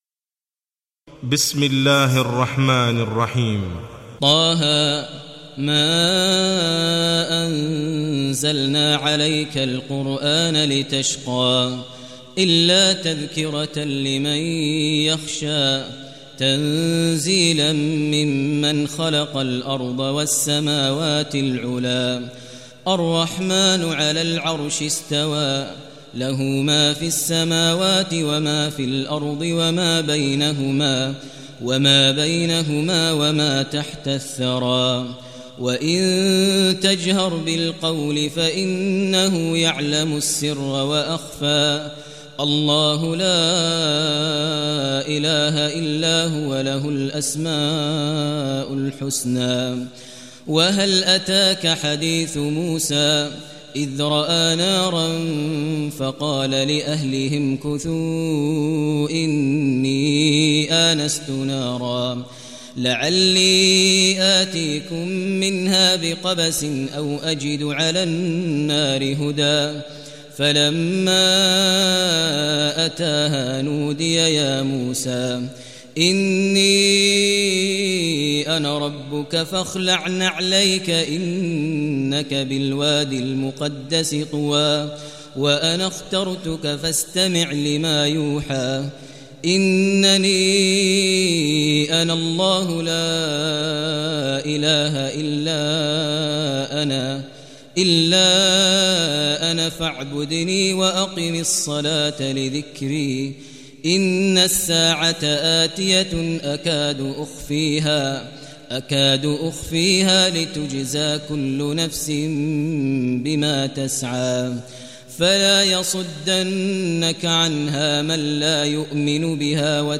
Surah Taha Recitation by Maher al Mueaqly
Surah Taha, listen online mp3 tilawat / recitation in Arabic recited by Imam e Kaaba Sheikh Maher al Mueaqly.